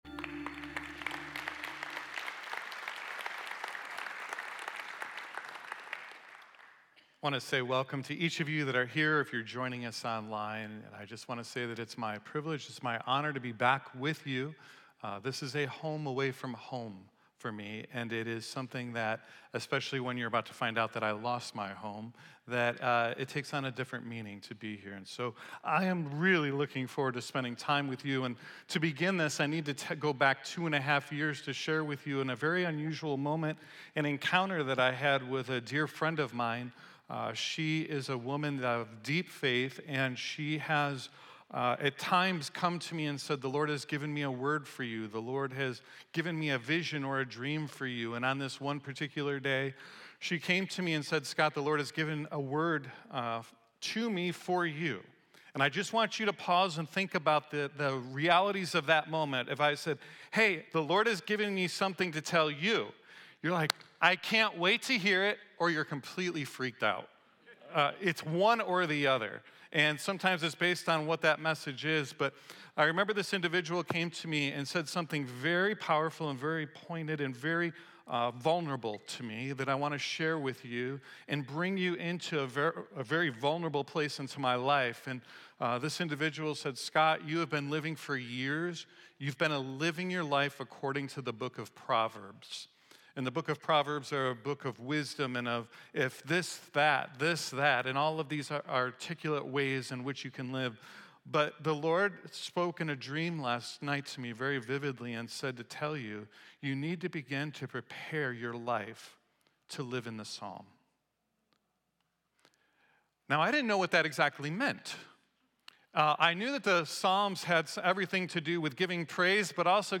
Today we continue our series “never the same” with a guest speaker